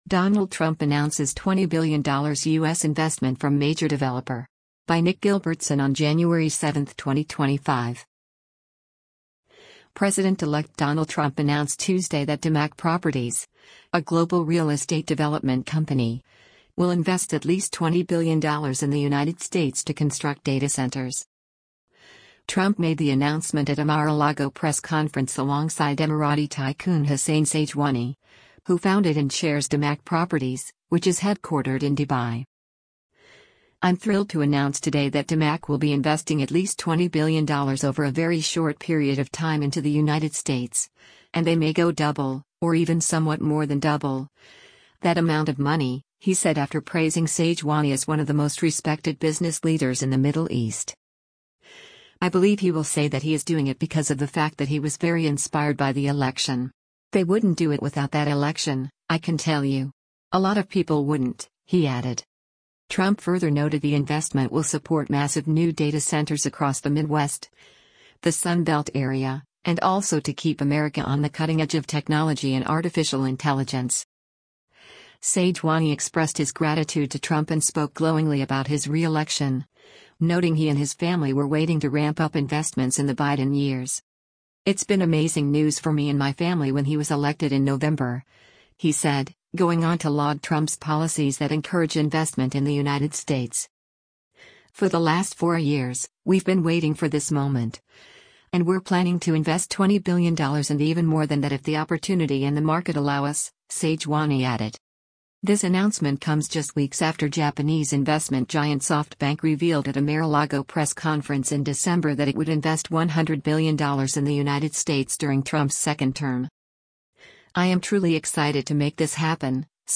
Trump made the announcement at a Mar-a-Lago press conference alongside Emirati tycoon Hussain Sajwani, who founded and chairs Damac Properties, which is headquartered in Dubai.